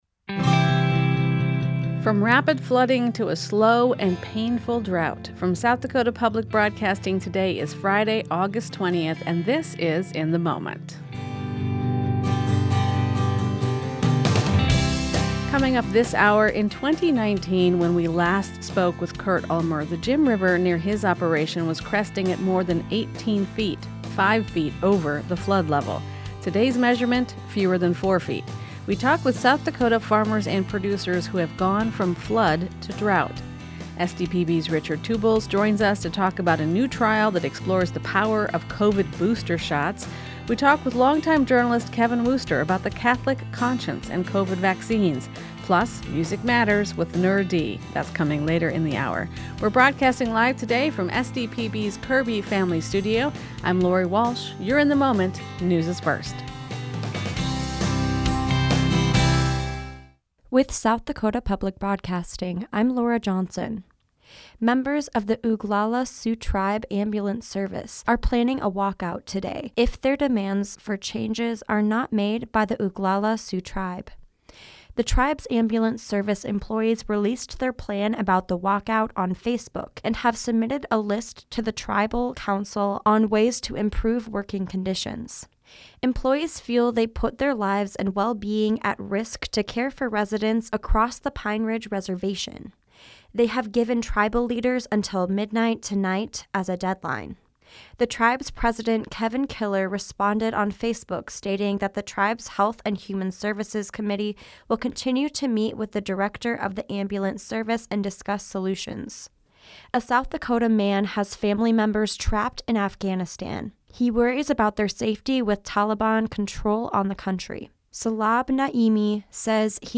In the Moment is SDPB’s daily news and culture magazine program.
Dusty Johnson is South Dakota's U.S. Representative.